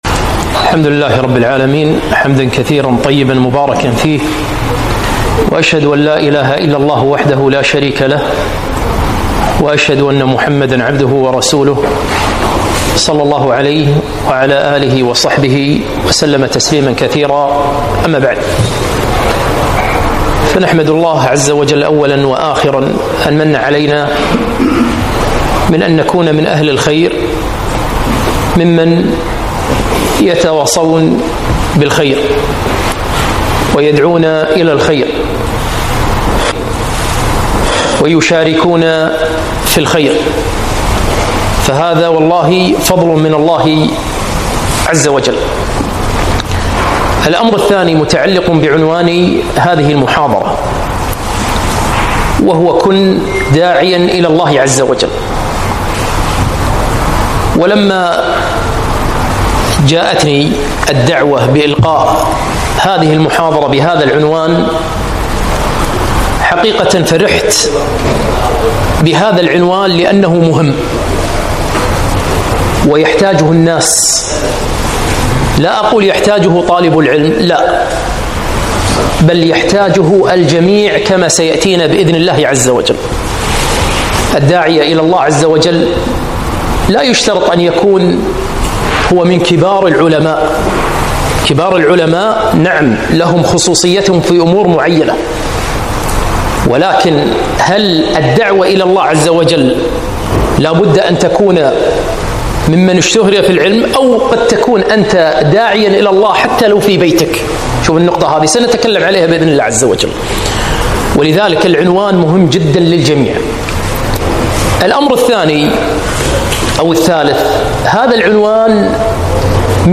محاضرة - كن داعيًا لله